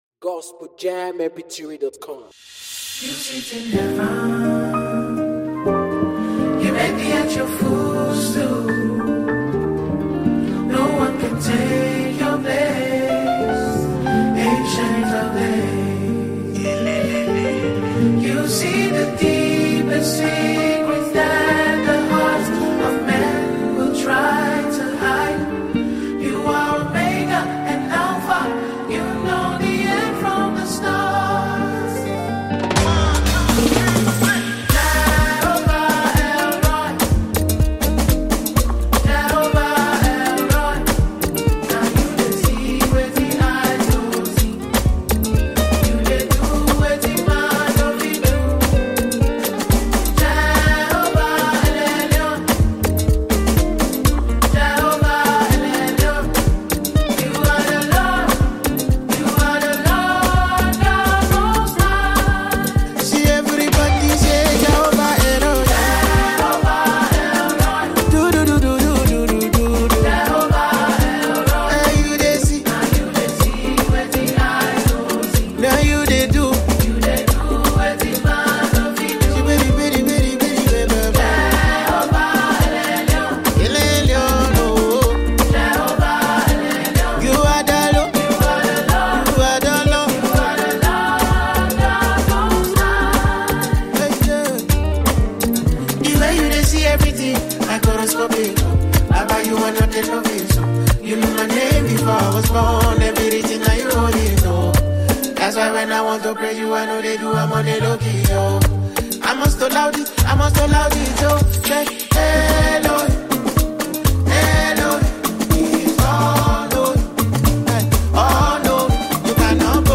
Nigerian Gospel Singer